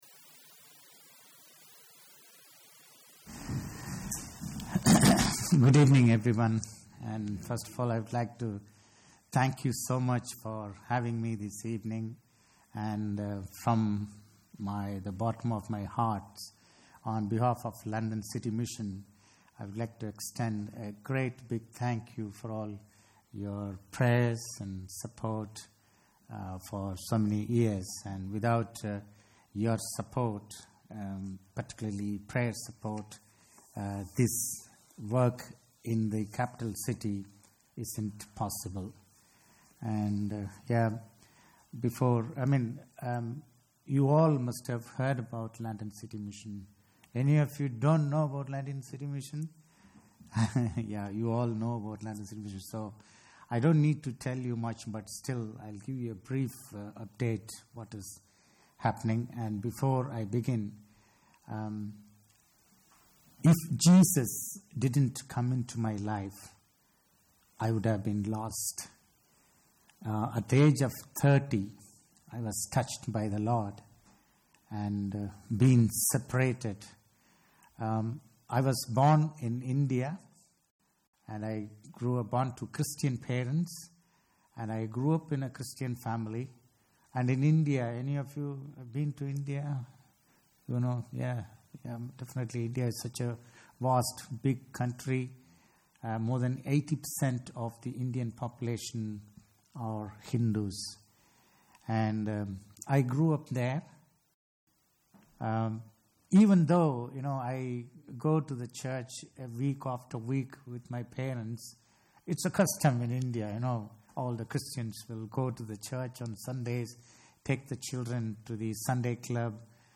London City Mission – Testimony & Message – What Boundaries are God Challenging You to Cross?